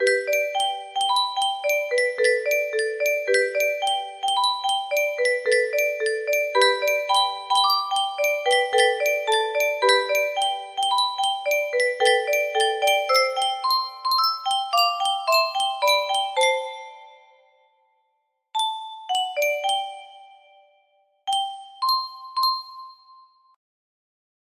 Clone of Unknown Artist - Untitled music box melody